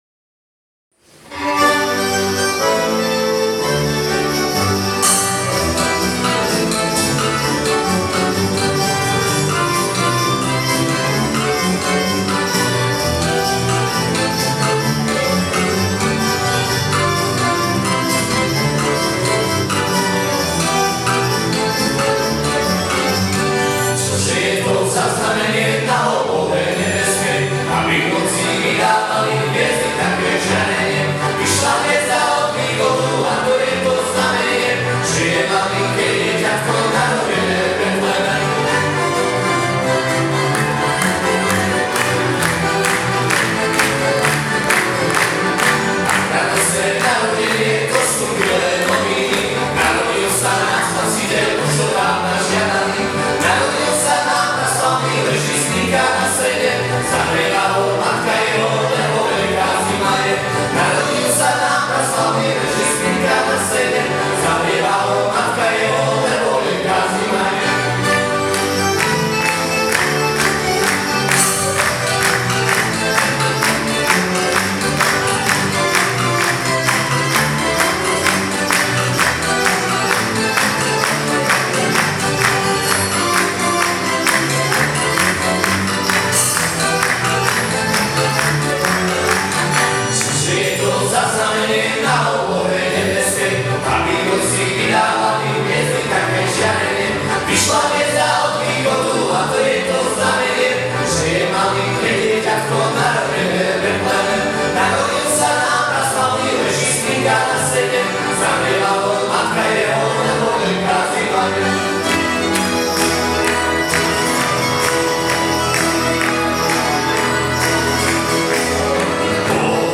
Rubrika: Křesťanské písně